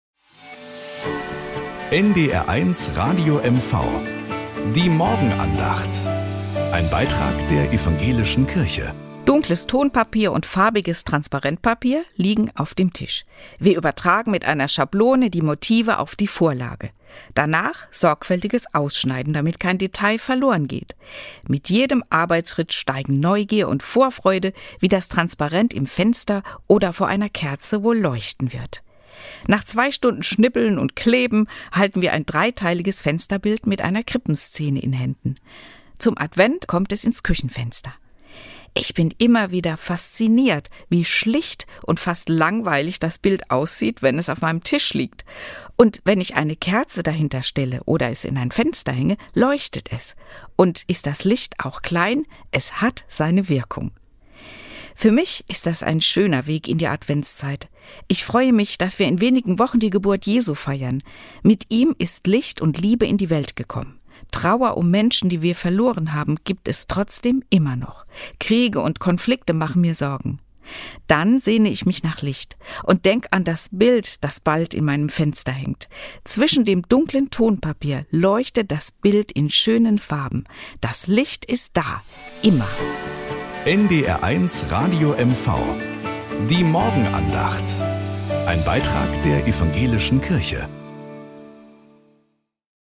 Morgenandacht.